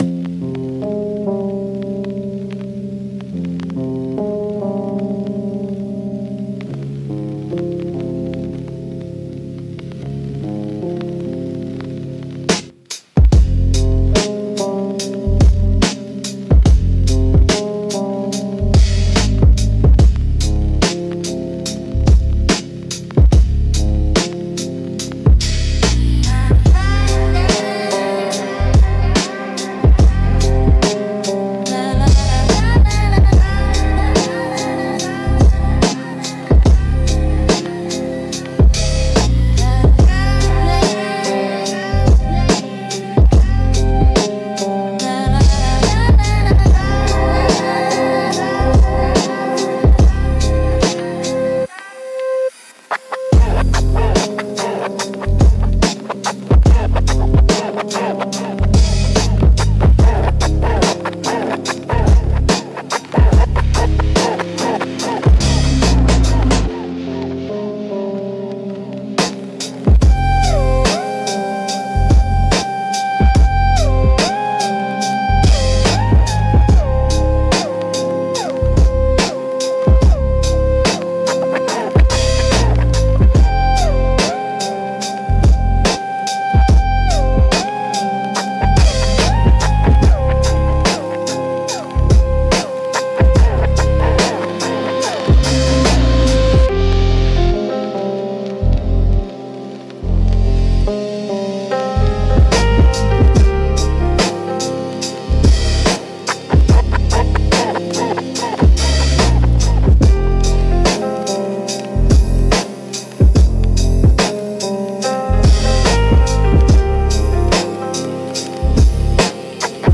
Instrumental - Cipher on the Corner